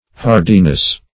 Hardiness \Har"di*ness\ (-d[i^]*n[e^]s), n.
hardiness.mp3